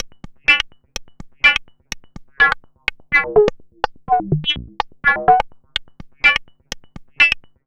tx_perc_125_squeaker.wav